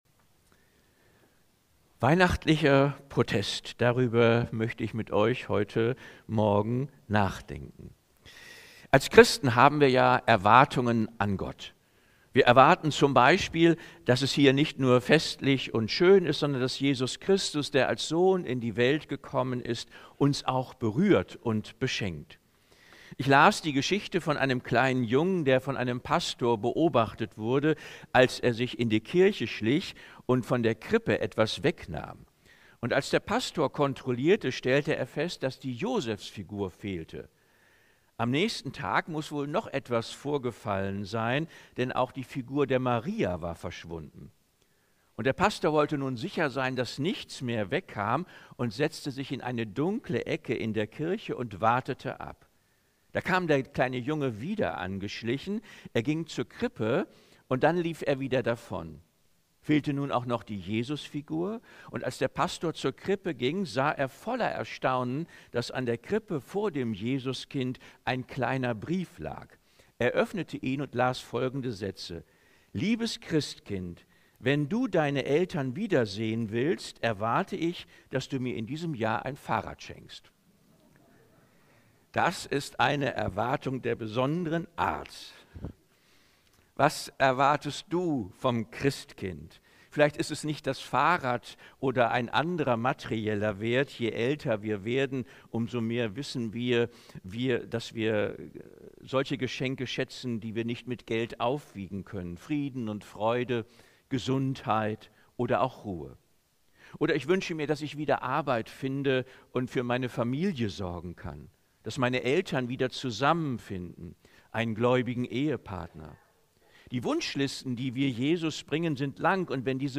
Predigten aus der Baptistengemeinde Leer